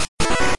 标签： 就绪 战斗 VS 开始 战斗 决斗 播音员 战斗 开始
声道立体声